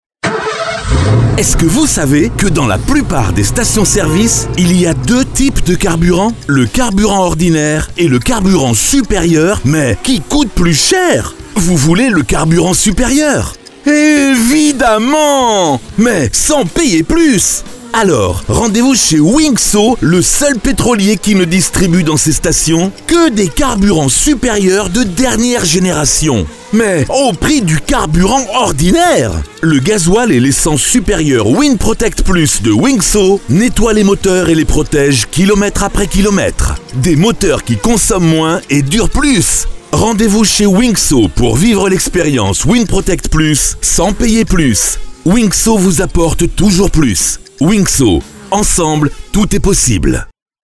Nous pouvons créer pour vous les textes de vos spots publicitaires et réalisé ensuite l’enregistrement et le montage sur musique.